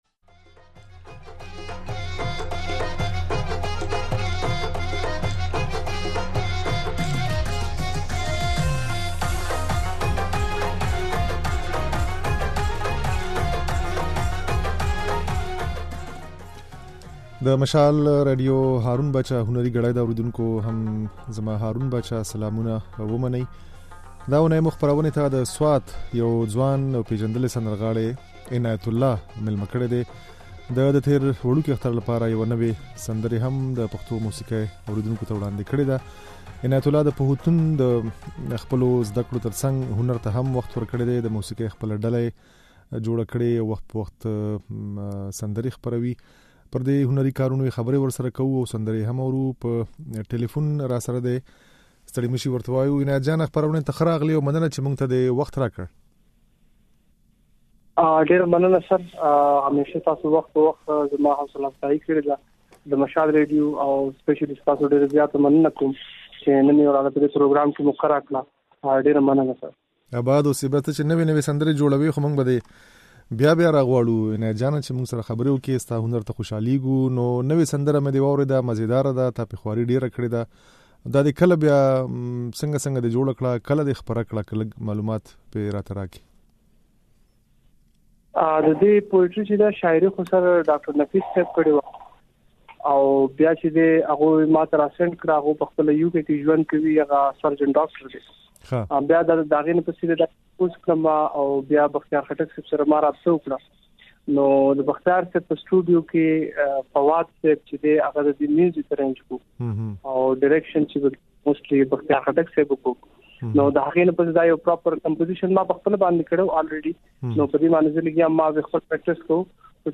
د نوموړي دا خبرې او د نوې سندرې ترڅنګ يې ځينې پخوانۍ هغه هم د غږ په ځای کې اورېدای شئ.